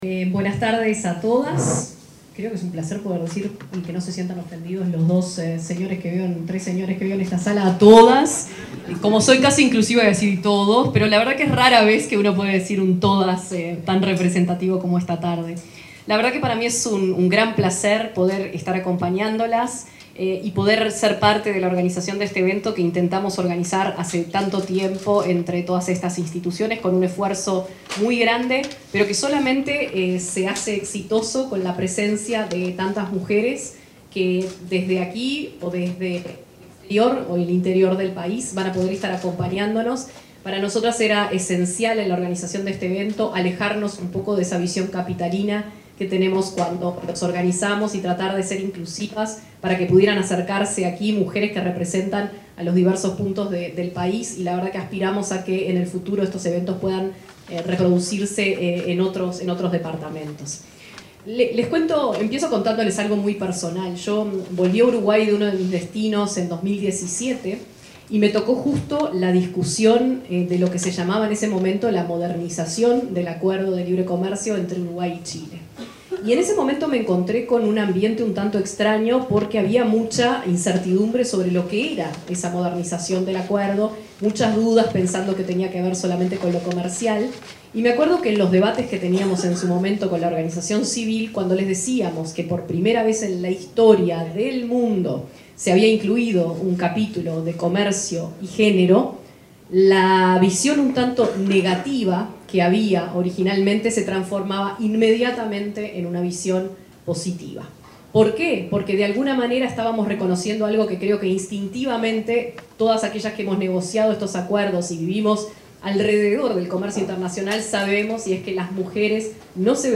Palabras de autoridades en el taller Mujeres Líderes Uruguay-Chile: Capacidades para la Internacionalización